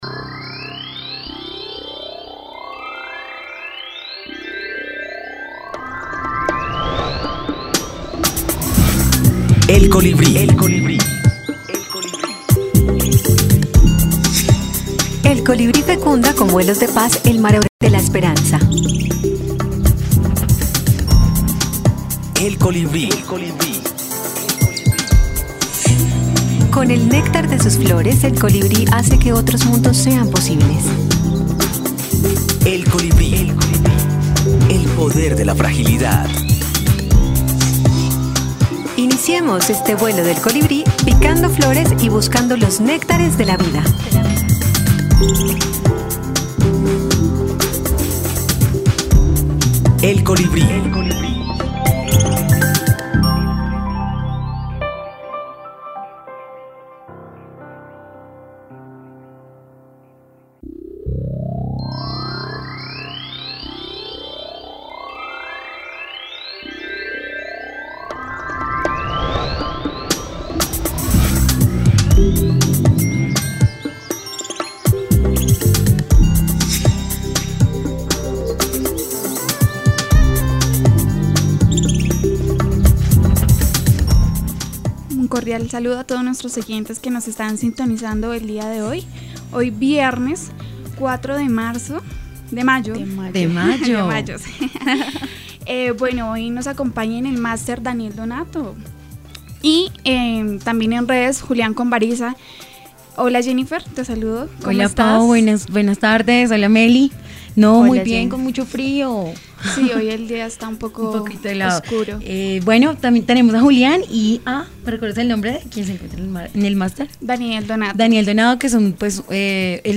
Lo que hace innovadora esta pieza, es la propuesta femenina ya que en el campo de juego hay solo mujeres al igual que en la mesa narrativa.